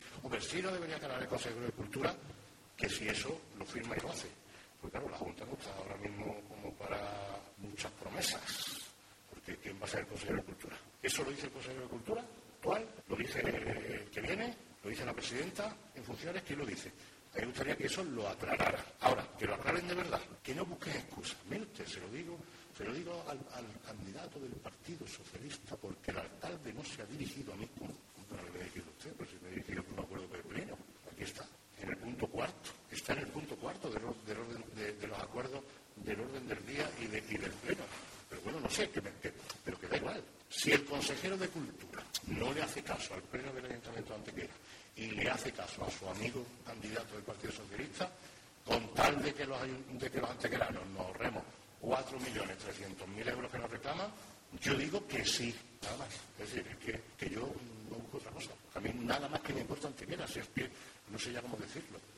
El alcalde de Antequera, Manolo Barón, ha realizado unas declaraciones ante la prensa en la tarde de hoy miércoles referente al “ofrecimiento” dado hoy por el actual consejero de Cultura en funciones, Luciano Alonso, para intercambiar la deuda que la Junta de Andalucía le exige al Ayuntamiento (por no haber acabado el “faraónico” proyecto del Palacio de Ferias promovido por el anterior Equipo de Gobierno del PSOE) por un pago en especie del edificio y los terrenos en sí.